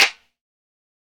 Perc (17).wav